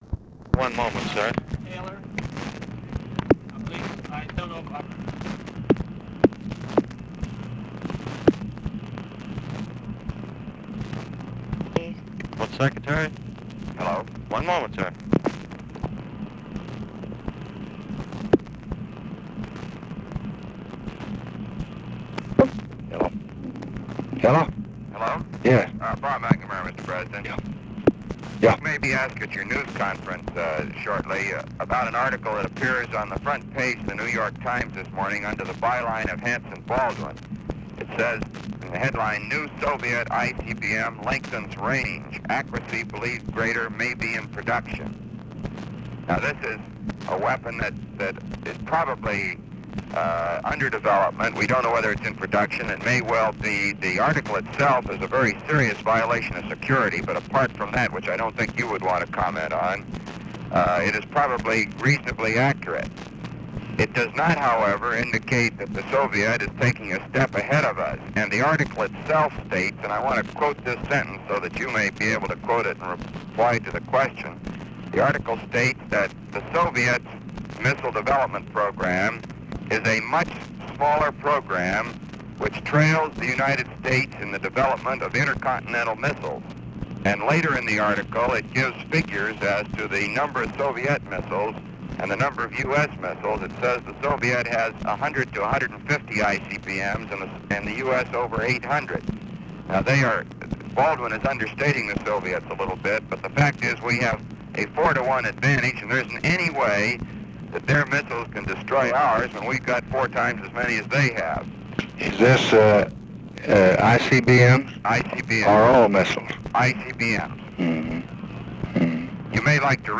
Conversation with ROBERT MCNAMARA, July 18, 1964
Secret White House Tapes